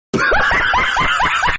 Mutahar Laughing Sound Effect Free Download
Mutahar Laughing